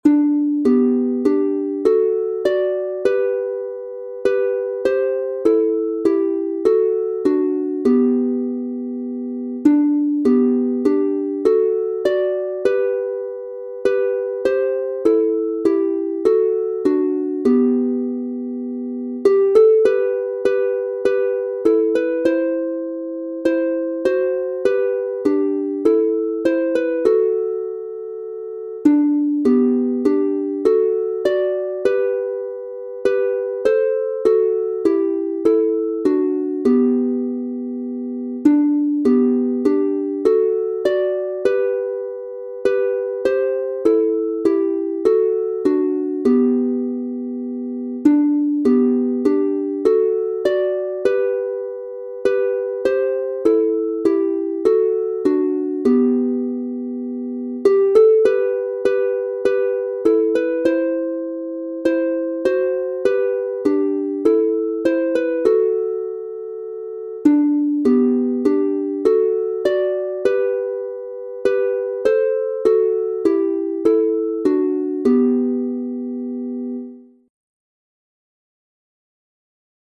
Hörprobe: (elektronisch eingespielt)